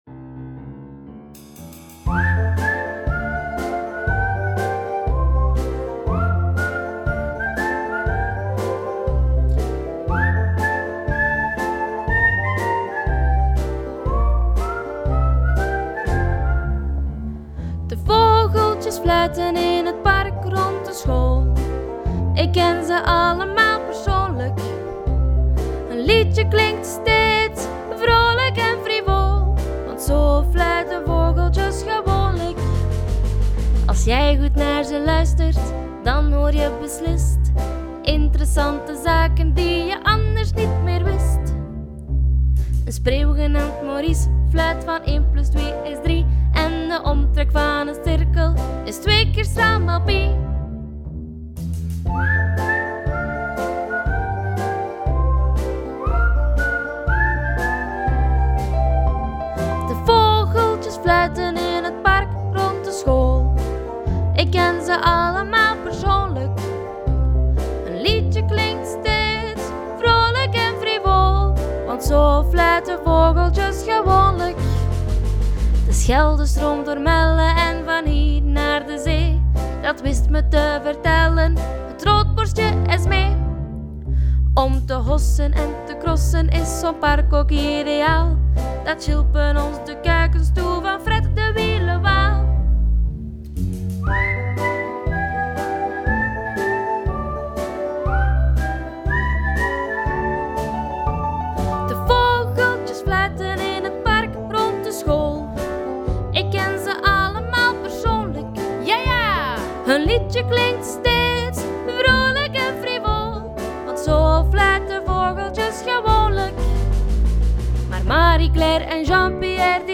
Schoollied
Ontdek het vrolijke schoollied van Gemeenteschool De Parkschool in Melle.